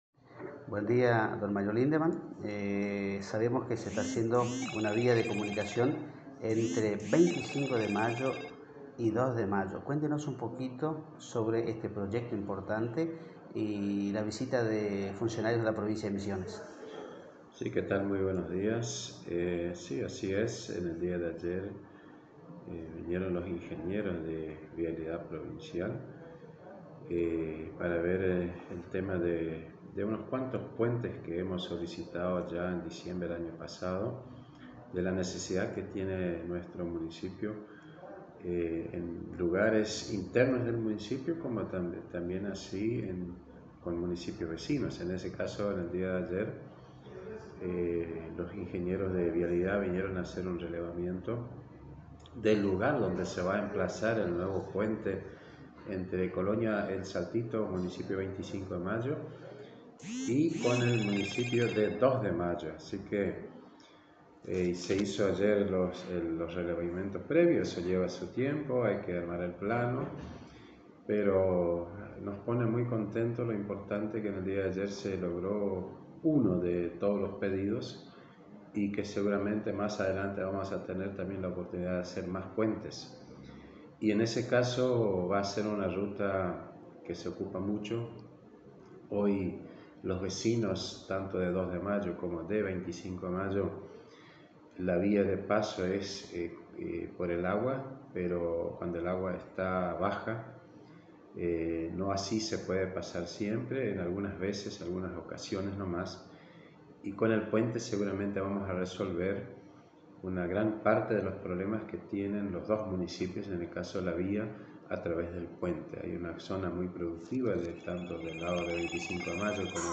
Audio: Intendente Mario Lindemann